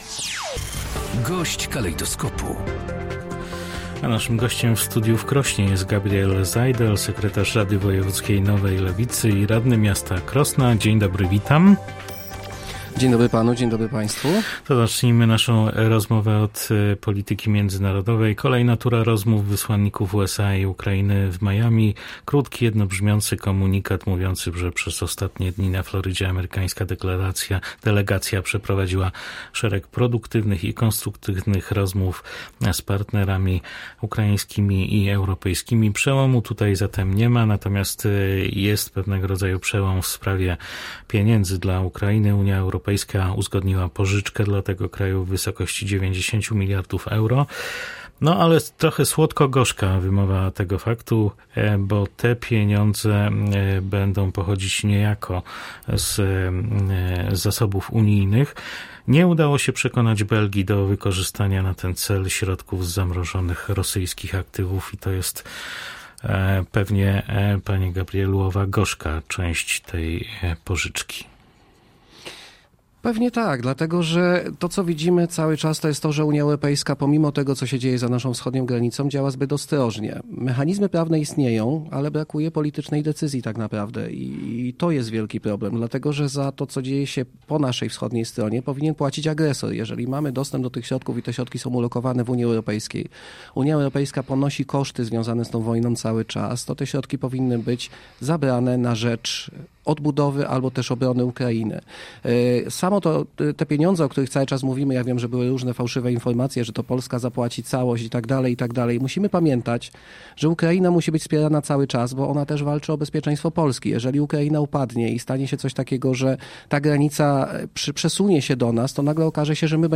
Chociaż rozmowy USA i Ukrainy na Florydzie nie przyniosły przełomu, istotna jest decyzja Unii Europejskiej o udzieleniu Ukrainie pożyczki w wysokości 90 miliardów euro – mówi Gabriel Zajdel, sekretarz Rady Wojewódzkiej Nowej Lewicy i radny Krosna.